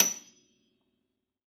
53f-pno28-F6.aif